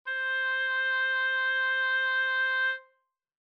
This is the sound of a a single tone, played by an oboe:
Oboe C
What you can also see is the vibrato, the player added as there are quite a few wavy lines in the spectrum 🙂
tut_hs_oboe.mp3